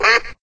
quack_2_1_quack.ogg